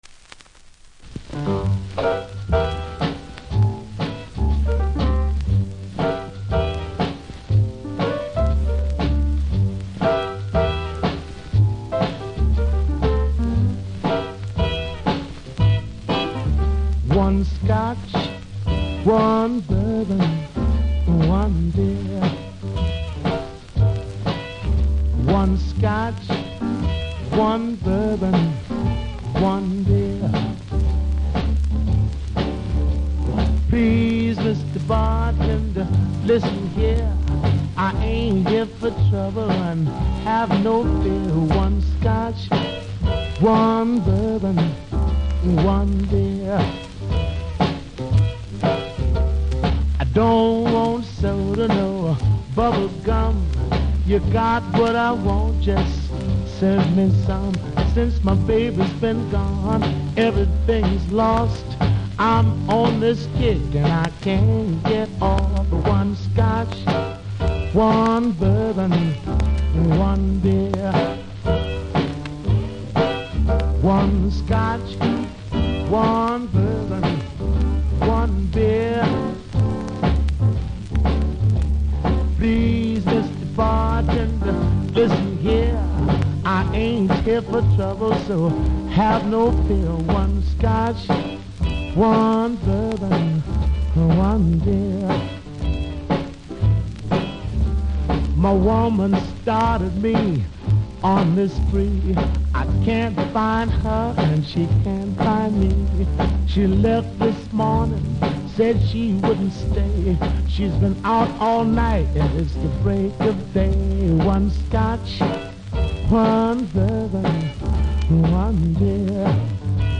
ジャマイカ盤で所々プレスノイズありますので試聴で確認下さい。